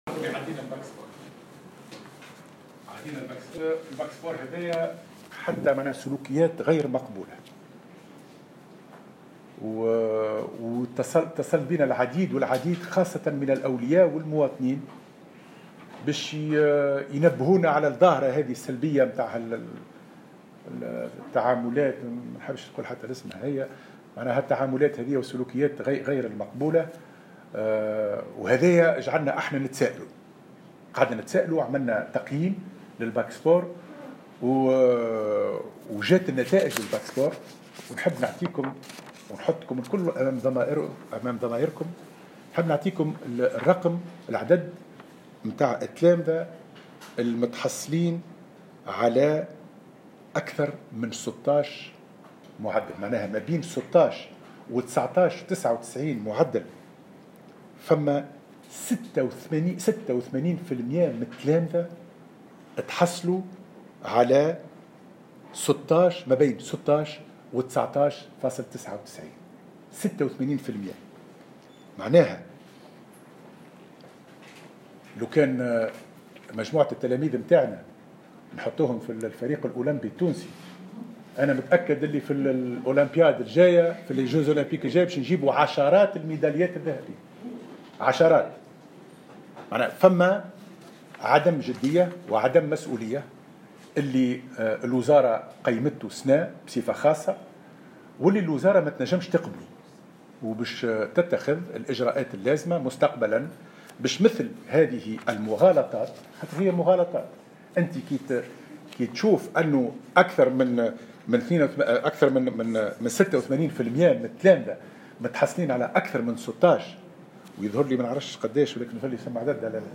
قال وزير التربية حاتم بن سالم في ندوة صحفية اليوم الاثنين إنه سيتم اتخاذ اجراءات مستقبلا بخصوص نظام "الباك سبور".